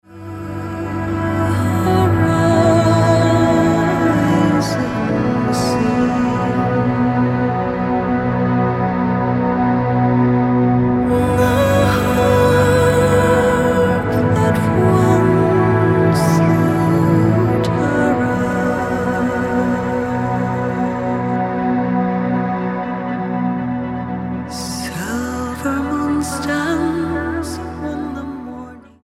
STYLE: Celtic